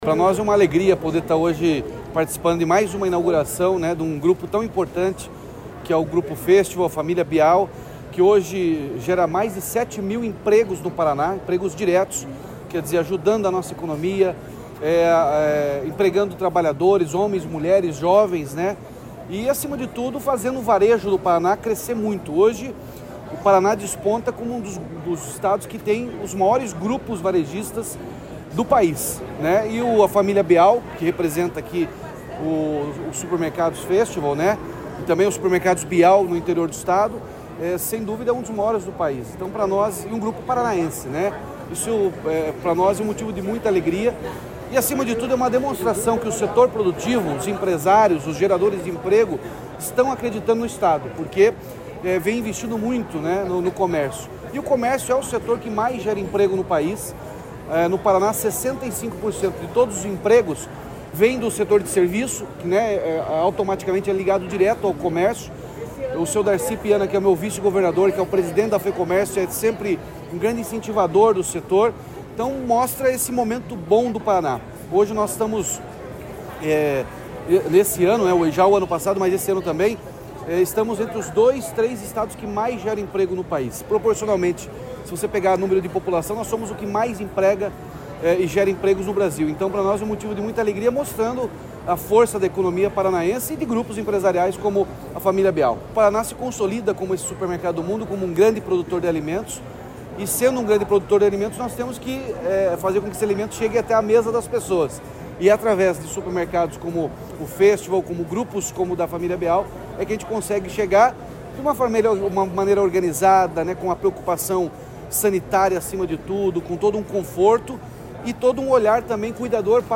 Sonora do governador Ratinho Junior sobre a inauguração do 37º Festval